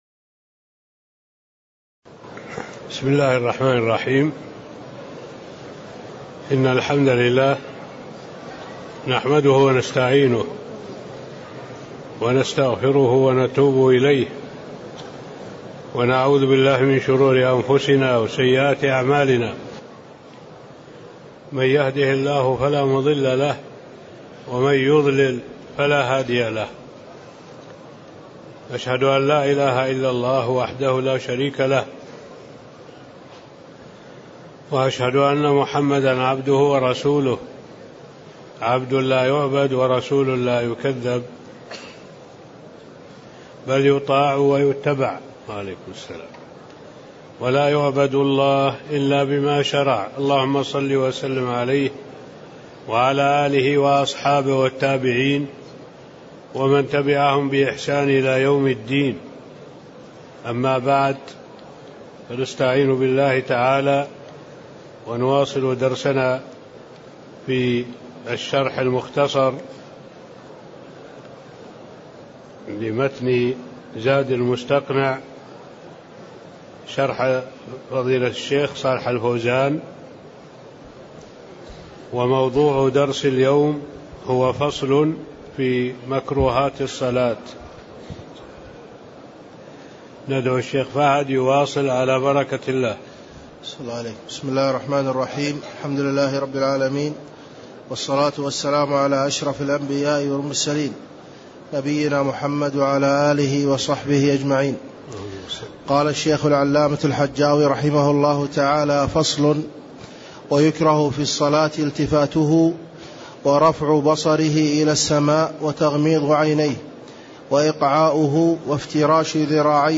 تاريخ النشر ١٩ جمادى الأولى ١٤٣٤ هـ المكان: المسجد النبوي الشيخ: معالي الشيخ الدكتور صالح بن عبد الله العبود معالي الشيخ الدكتور صالح بن عبد الله العبود باب مكروهات الصلاة (05)